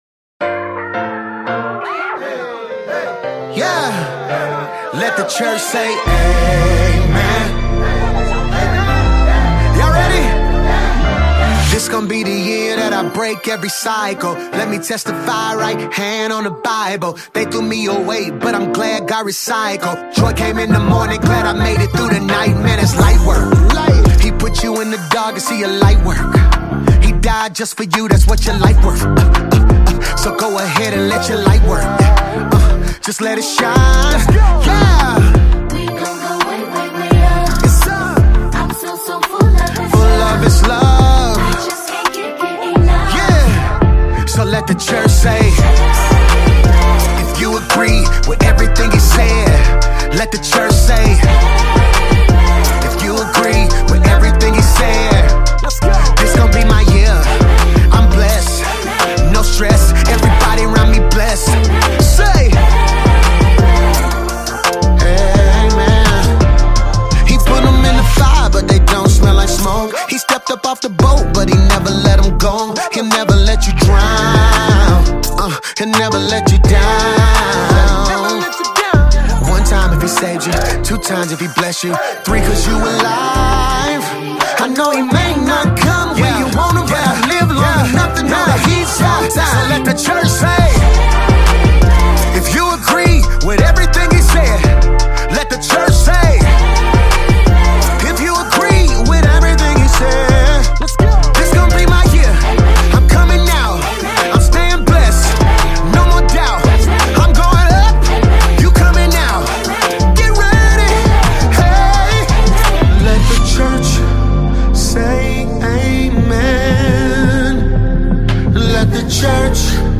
powerful and uplifting 2025 gospel single
American Gospel Songs Gospel Songs